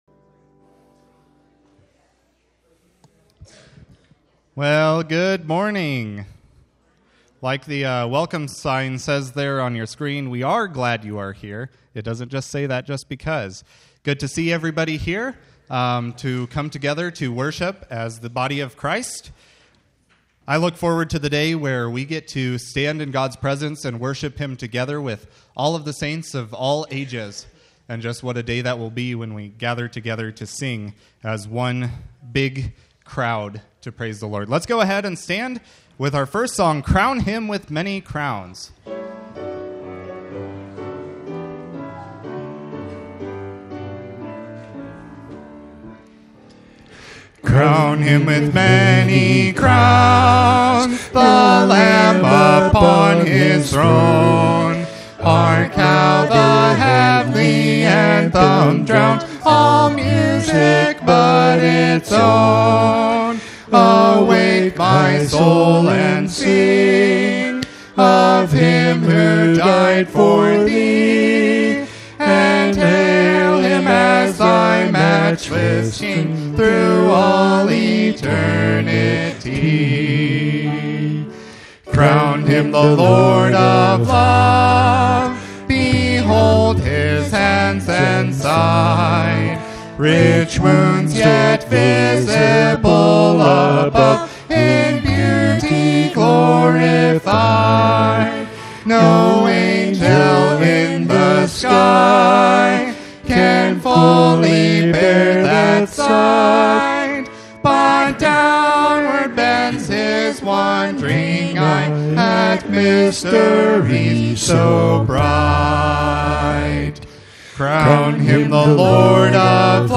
Audio Sermon Series • Union Valley Bible Church
Service-11-3-24.mp3